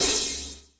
35CYMB01  -R.wav